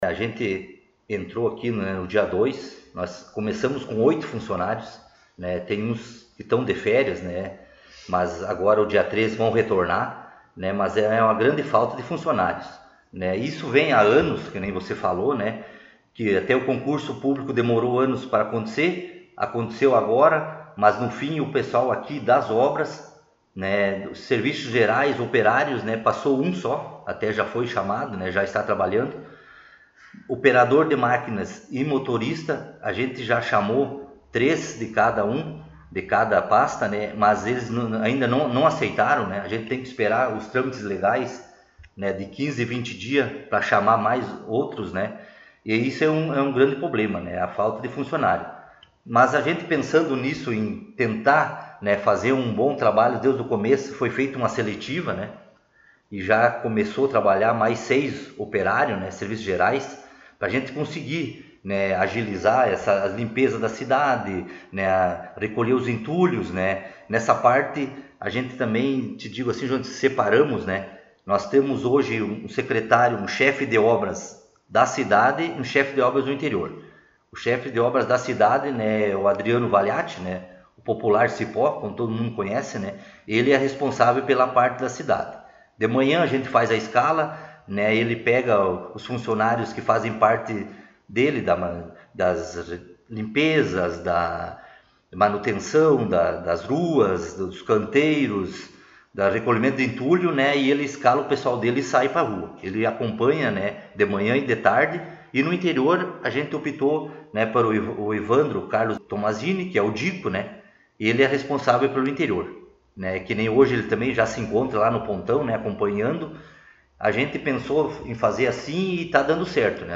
Secretário Municipal de Obras concedeu entrevista